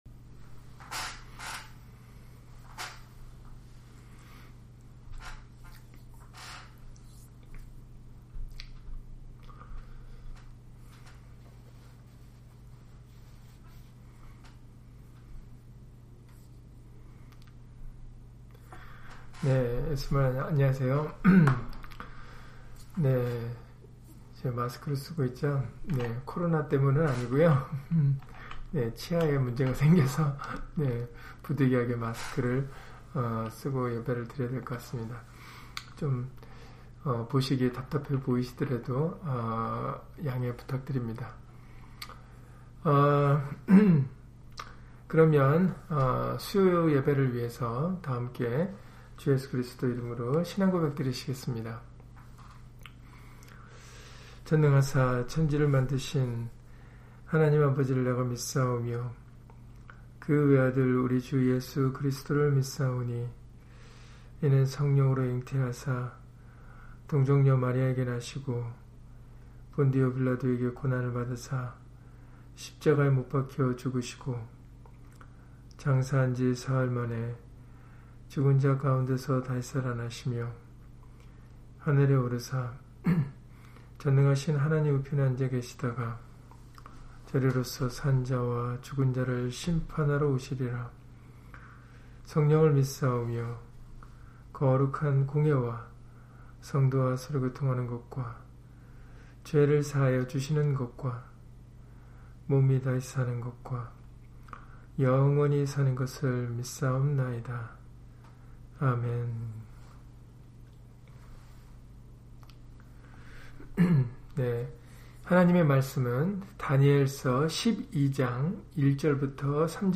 다니엘 12장 1-3절 [책에 기록된 모든 자가 구원을 얻을 것이라] - 주일/수요예배 설교 - 주 예수 그리스도 이름 예배당